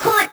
VEC3 Percussion 033.wav